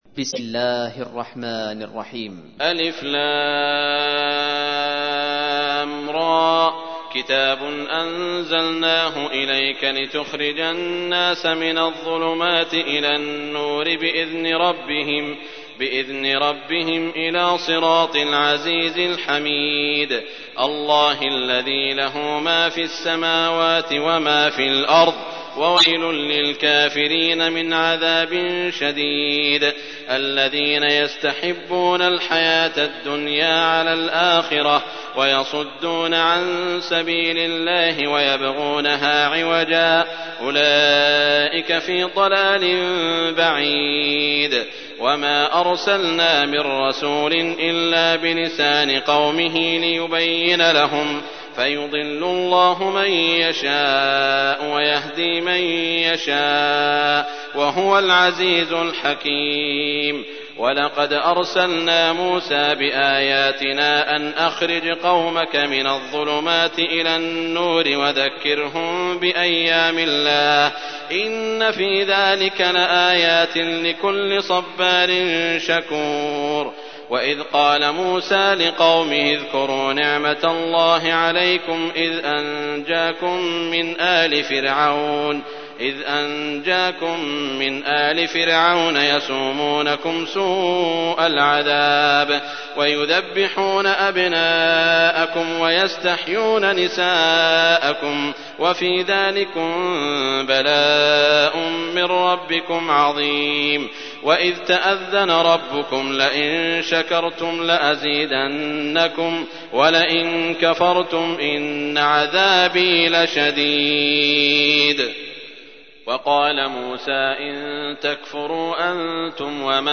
تحميل : 14. سورة إبراهيم / القارئ سعود الشريم / القرآن الكريم / موقع يا حسين